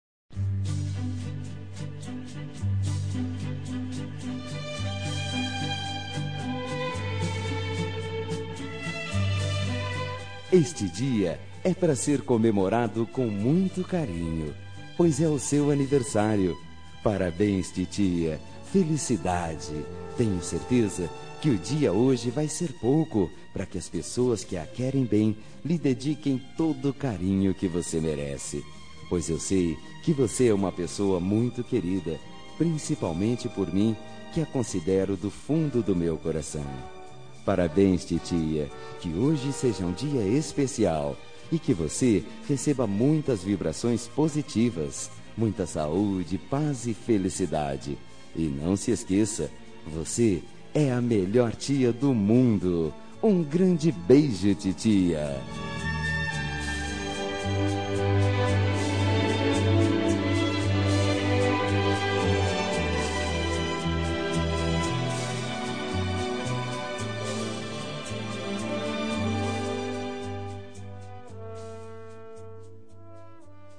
Telemensagem Aniversário de Tia – Voz Masculina – Cód: 2020